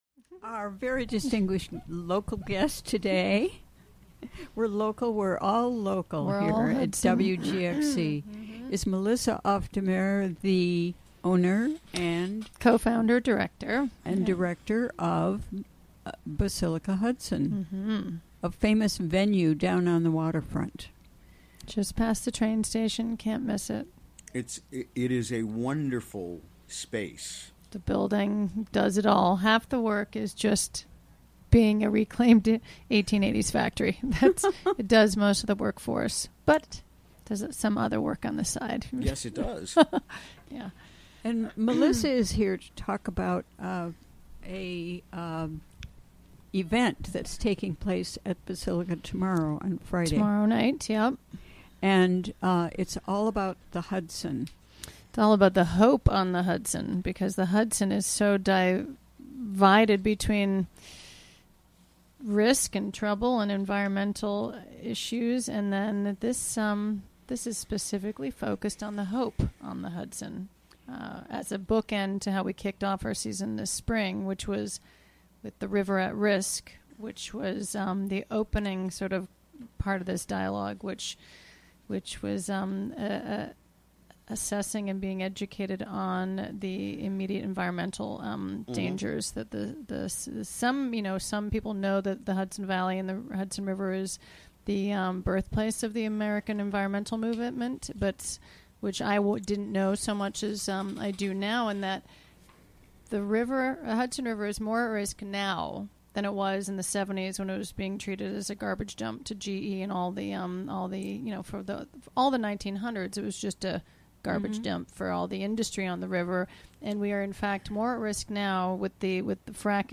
Recorded during the WGXC Afternoon Show on October 26, 2017.